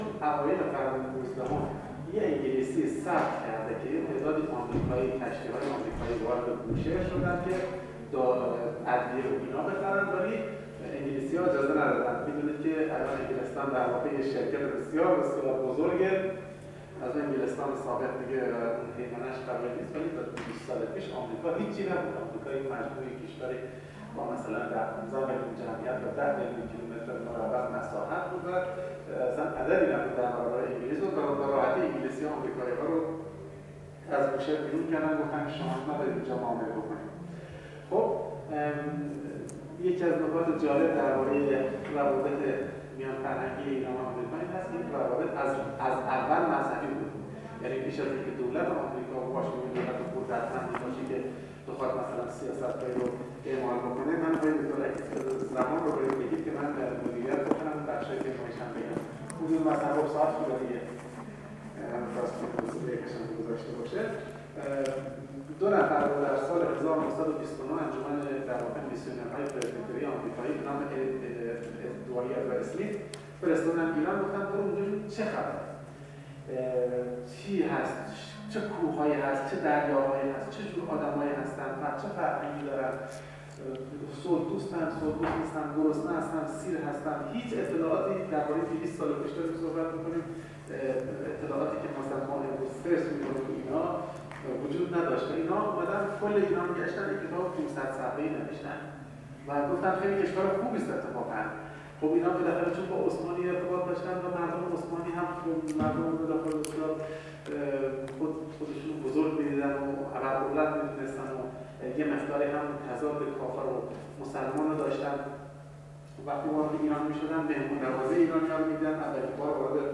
به گزارش خبرنگار ایکنا، دومین بخش نشست بازتاب انقلاب اسلامی در آثار اندیشمندان جهانی، 17 بهمن ماه در دانشکده حقوق و علوم سیاسی دانشگاه تهران برگزار شد.